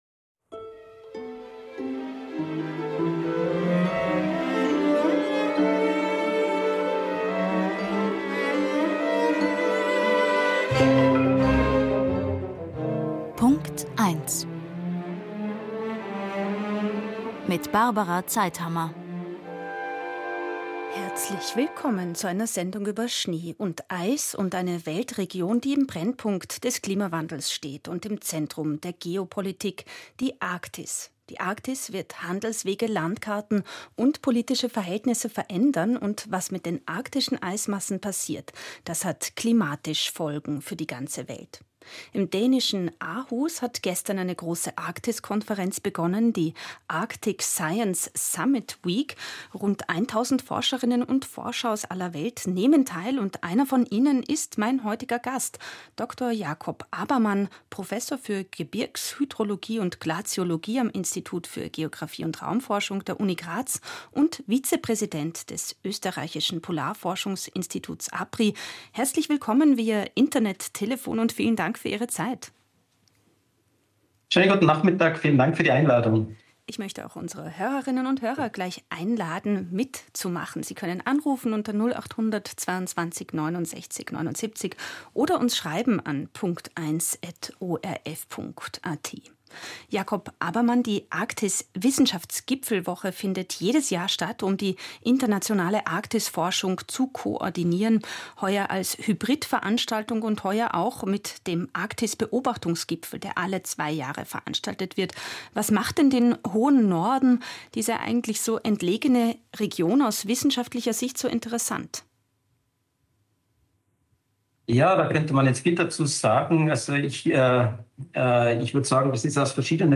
Radiosendung „Punkt Eins“